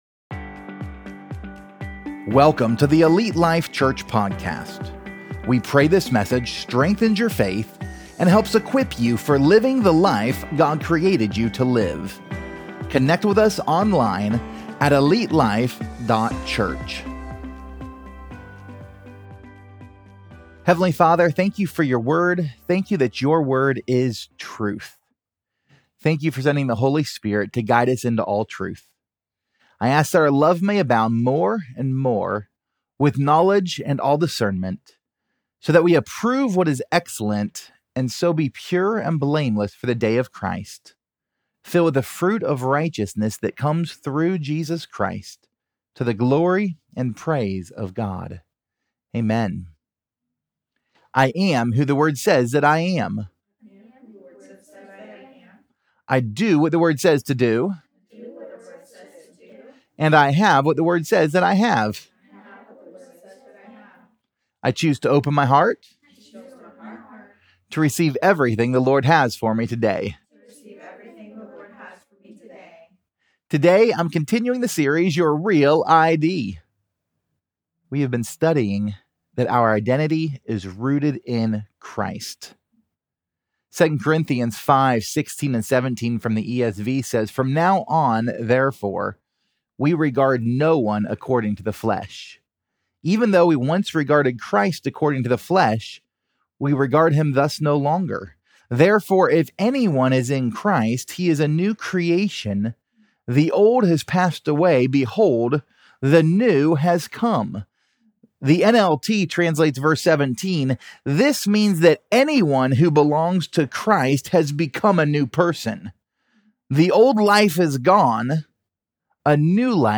Pt 19: Rich | Your REAL ID Sermon Series